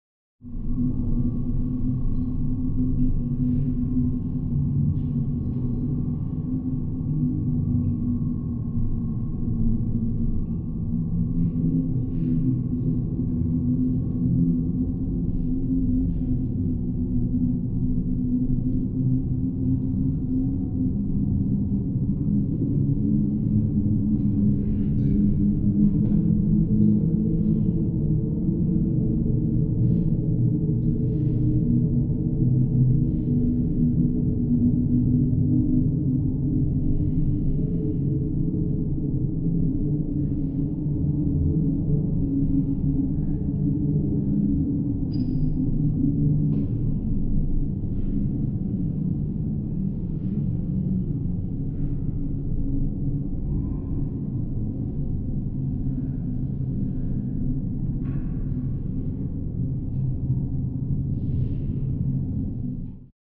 interieur-vaisseau-spatialogg.ogg